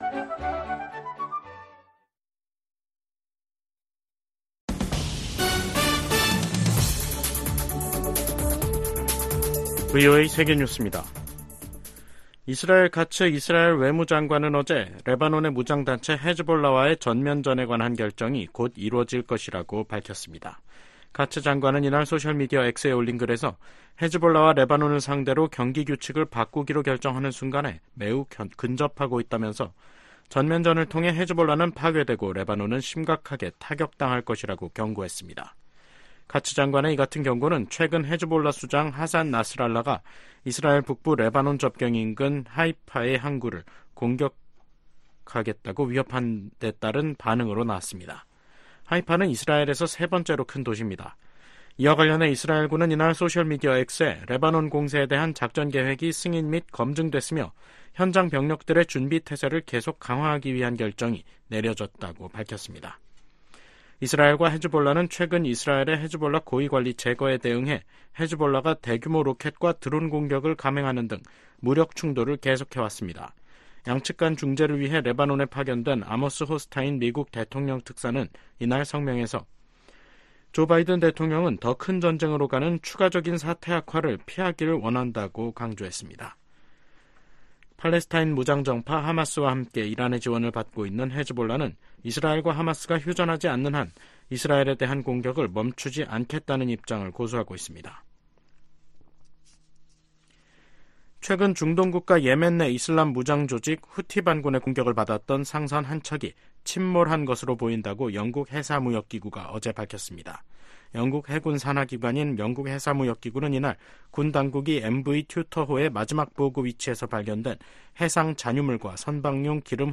VOA 한국어 간판 뉴스 프로그램 '뉴스 투데이', 2024년 6월 19일 3부 방송입니다. 김정은 북한 국무위원장과 블라디미르 푸틴 러시아 대통령이 오늘, 19일 평양에서 정상회담을 갖고 포괄적 전략 동반자 협정에 서명했습니다. 미국 백악관은 푸틴 러시아 대통령이 김정은 북한 국무위원장에게 외교가 한반도 문제 해결의 유일한 해법이라는 메시지를 전달해야 한다고 촉구했습니다.